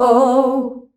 OUUH  C.wav